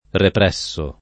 repr$SSo] — cfr. premere — dell’uso ant. le var., di tendenza ora più ora meno pop., ripriemere [riprL$mere] (col dittongo -ie- nelle sole forme rizotoniche), ripremere [ripr$mere], repremere [repr$mere]; e così, nel pass. rem., ripressi [ripr$SSi]; nel part. pass., ripresso [ripr$SSo] e anche riprimuto [riprim2to], ripremuto [riprem2to], repremuto [reprem2to]